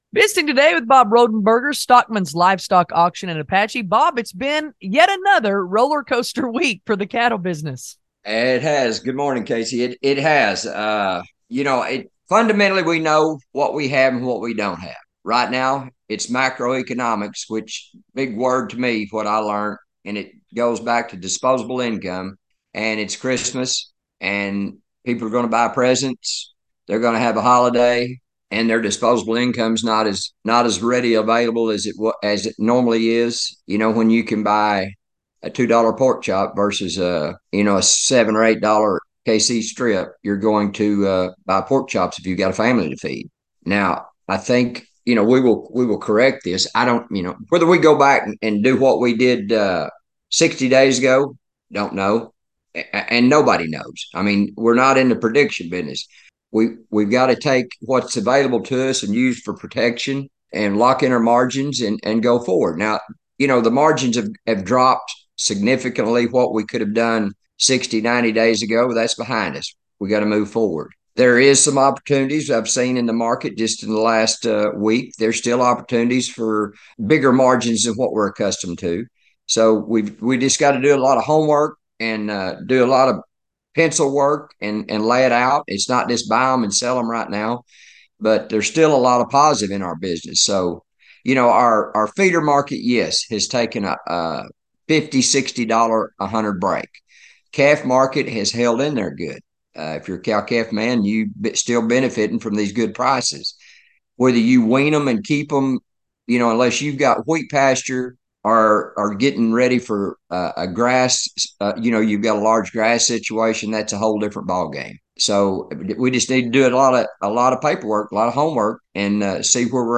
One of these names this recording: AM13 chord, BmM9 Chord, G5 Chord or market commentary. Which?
market commentary